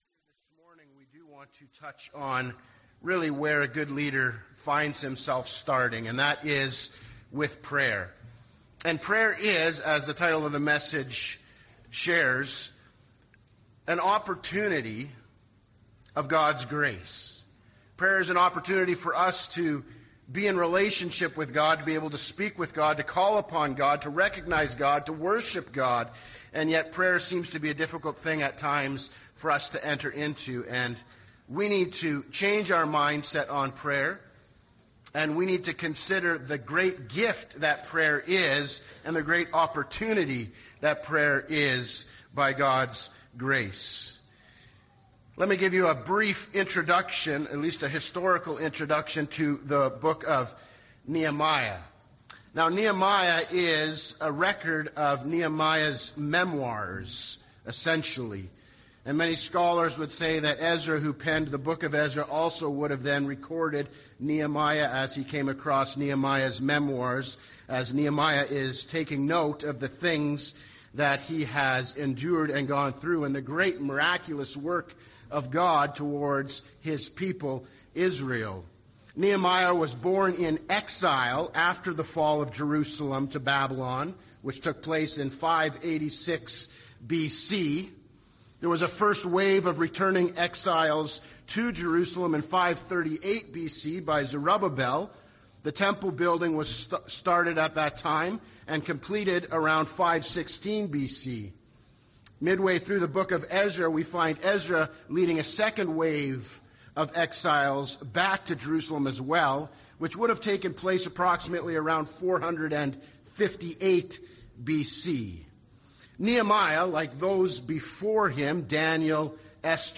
Sermon Prayer